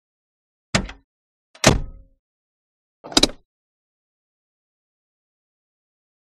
Automobile; Door Open and Close; Audi 80l Door And Hand Brake. Shot From Interior.